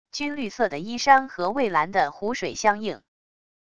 军绿色的衣衫和蔚蓝的湖水相应wav音频生成系统WAV Audio Player